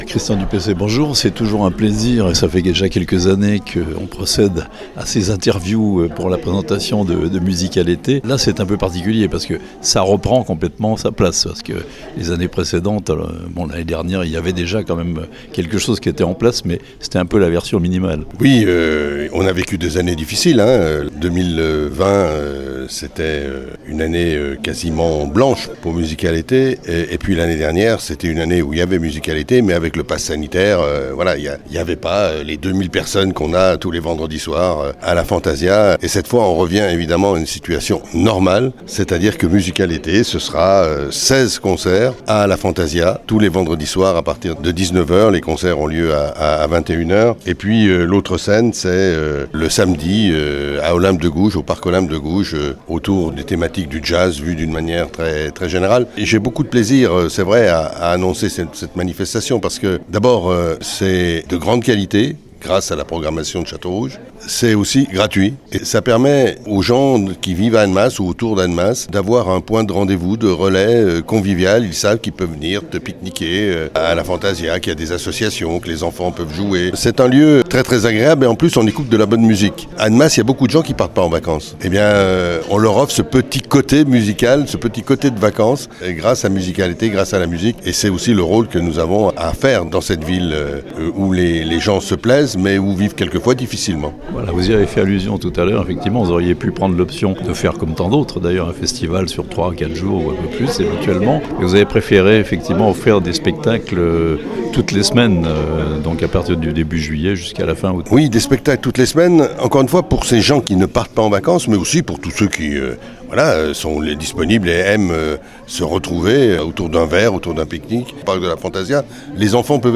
"Les Musical'Eté" à Annemasse, le festival gratuit reprend ses quartiers d'été (interviews)